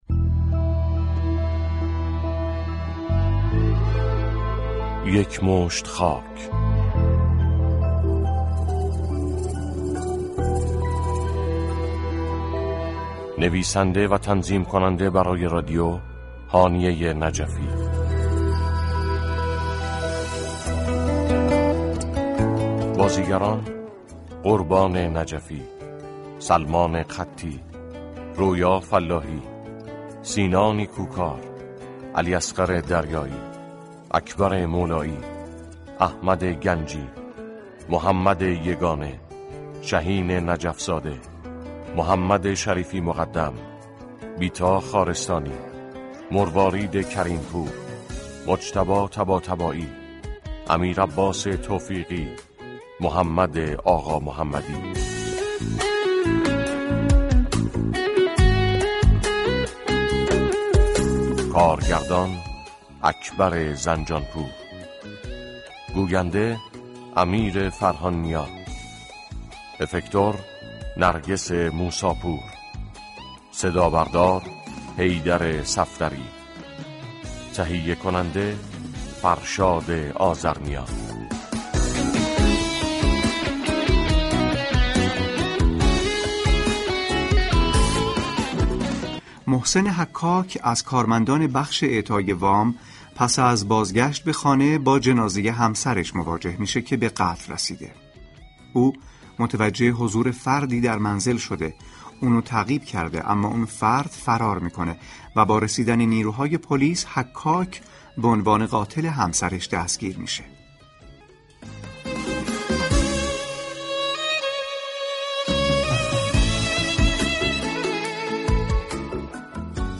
دوشنبه 25 دی ماه، شنونده سریال جدید پلیسی